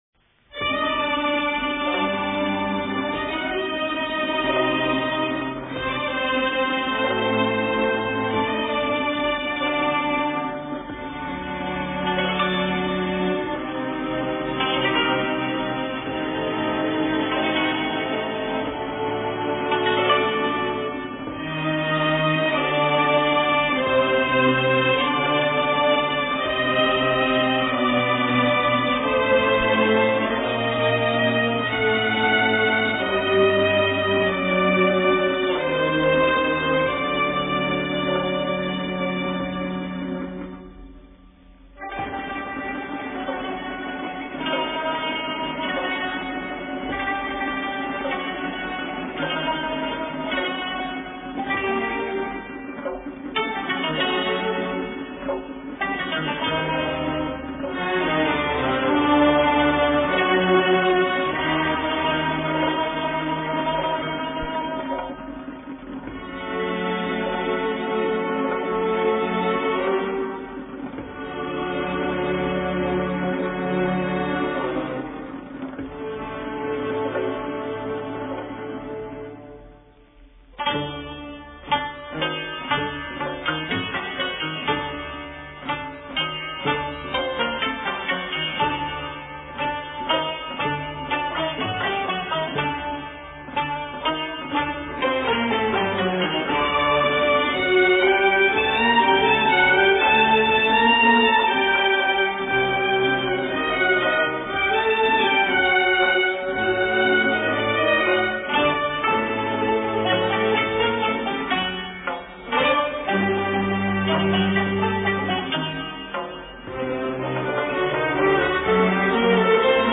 دستگاه: همایون